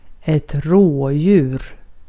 Uttal
Synonymer rå Uttal Okänd accent: IPA: /ˈroːˌjʉːr/ Ordet hittades på dessa språk: svenska Översättning Substantiv 1. karaca Artikel: ett .